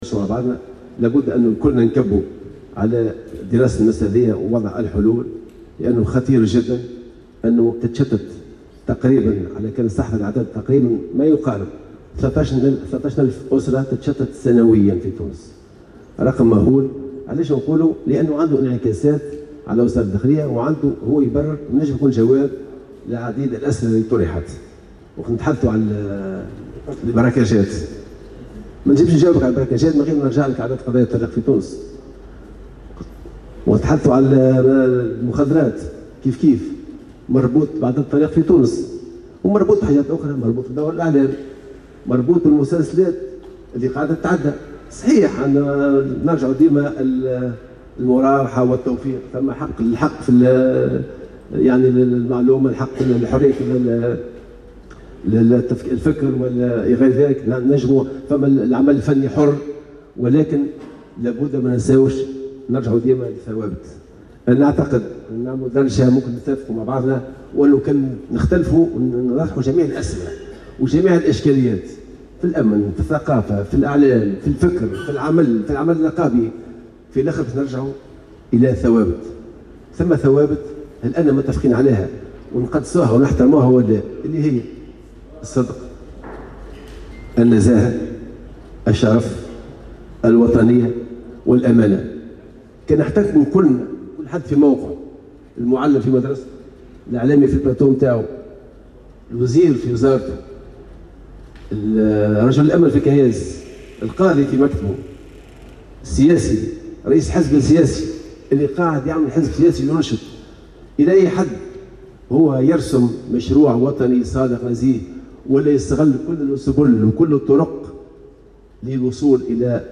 وأكد شرف الدين خلال لقاء مع الاعلاميين اليوم الخميس بنادي الأمن بسكرة، أنه لا يملك أية نوايا لرئاسة الحكومة، وأن موقفه من رئيس الجمهورية لا يدخل في خانة "التطبيل".